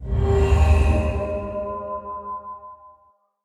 Minecraft Version Minecraft Version 1.21.5 Latest Release | Latest Snapshot 1.21.5 / assets / minecraft / sounds / block / beacon / power2.ogg Compare With Compare With Latest Release | Latest Snapshot